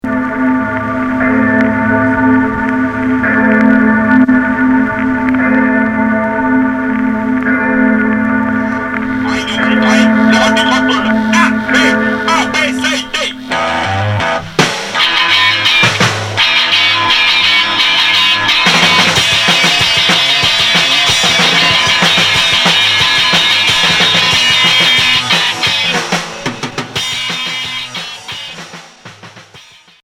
Garage Deuxième 45t retour à l'accueil